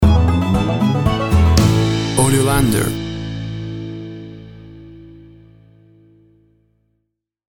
Classic country music sound.
WAV Sample Rate 16-Bit Stereo, 44.1 kHz
Tempo (BPM) 115